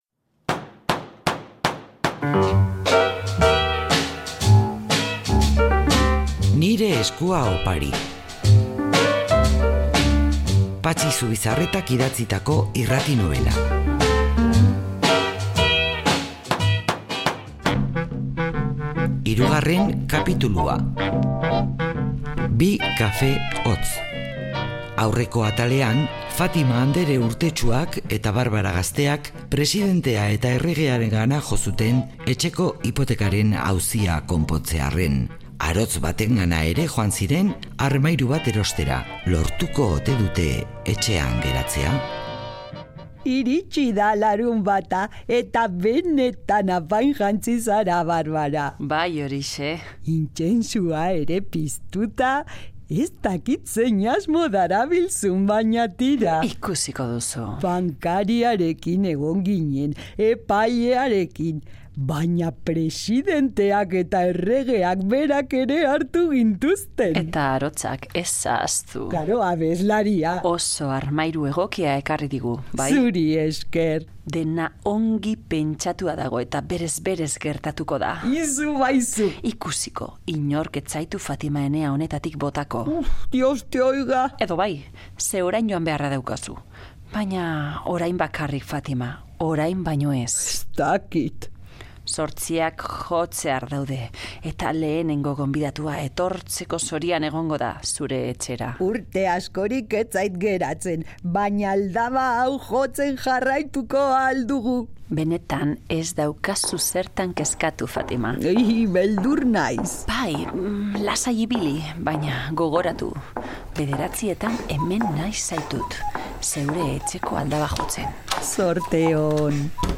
Radio Vitoria RADIO-TEATRO-URALDE 'Nire eskua opari' (3. kapitulua) Última actualización: 21/10/2015 16:45 (UTC+2) Patxi Zubizarretak idatzitako irrati-nobela.